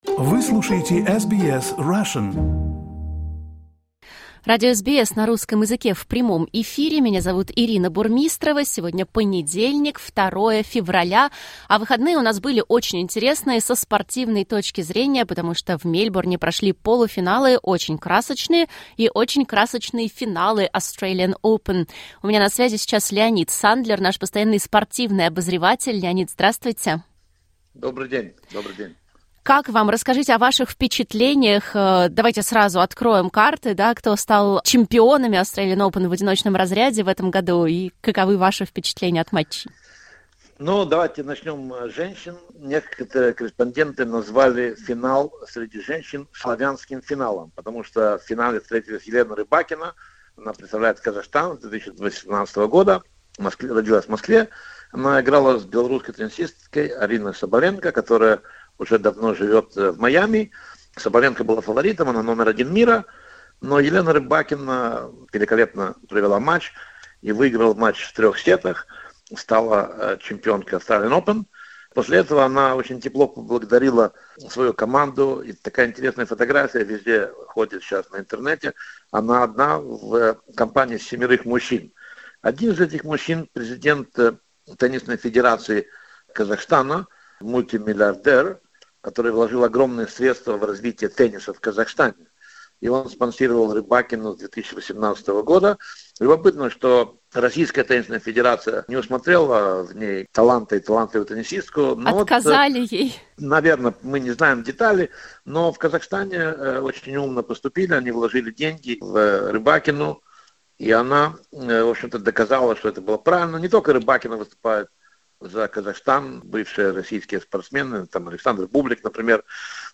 Спортивный обзор: Итоги Australian Open 2026